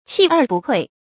器二不匱 注音： ㄑㄧˋ ㄦˋ ㄅㄨˋ ㄎㄨㄟˋ 讀音讀法： 意思解釋： 比喻東西有儲備，就不怕短缺。